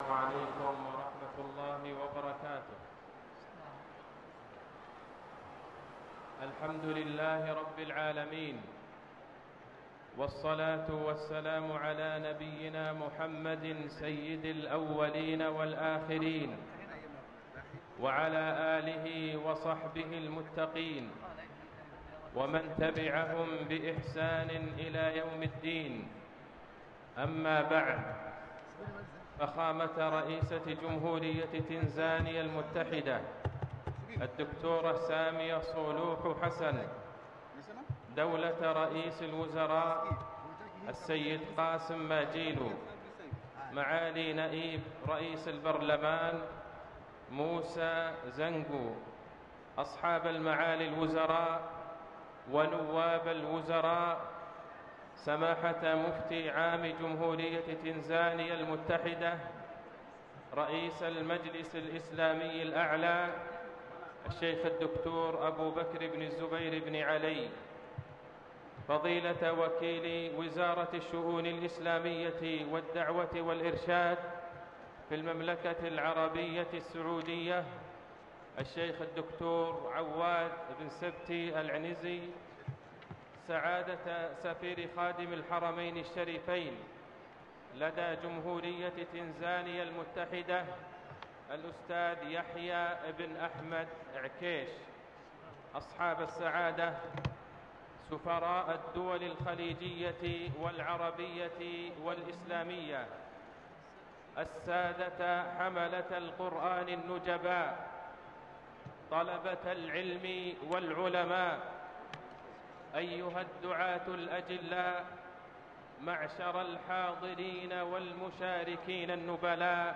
كلمة فضيلة الشيخ عبدالله البعيجان في الحفل الختامي لمسابقة القرآن الكريم الدولية في دولة تنزانيا > تلاوات و جهود الشيخ عبدالله البعيجان > تلاوات وجهود أئمة الحرم النبوي خارج الحرم > المزيد - تلاوات الحرمين